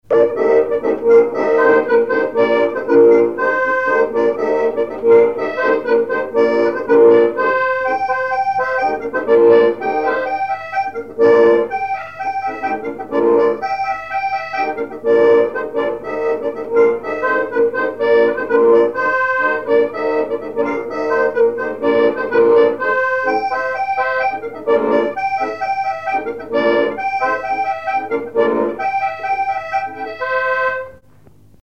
Mémoires et Patrimoines vivants - RaddO est une base de données d'archives iconographiques et sonores.
Chants brefs - A danser
instrumentaux à l'accordéon diatonique
Pièce musicale inédite